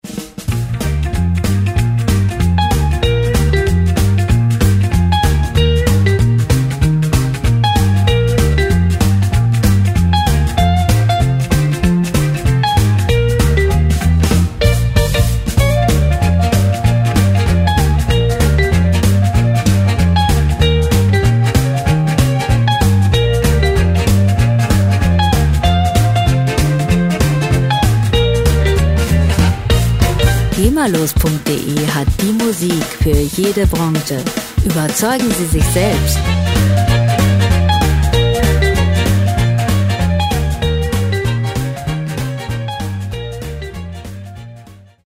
Musikstil: Jive
Tempo: 192 bpm
Tonart: B-Dur
Charakter: temporeich, frech